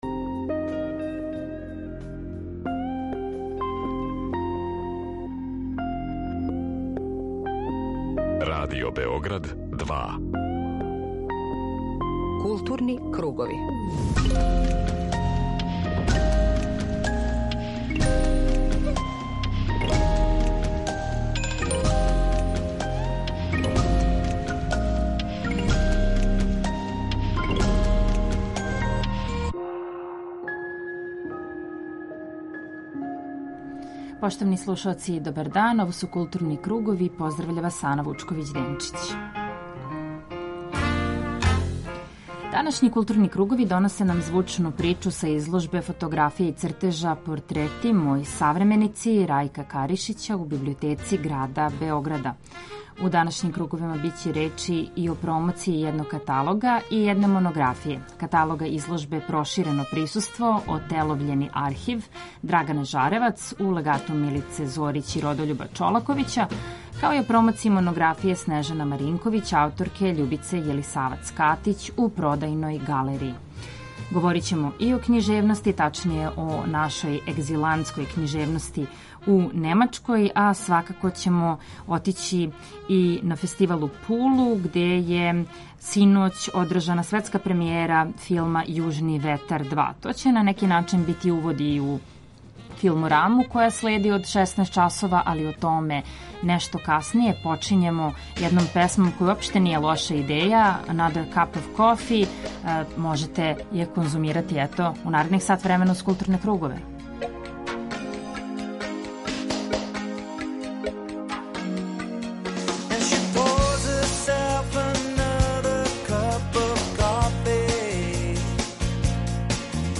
Централна културно-уметничка емисија Радио Београда 2.
Данашњи Културни кругови доносе нам звучну причу са изложбе фотографија и цртежа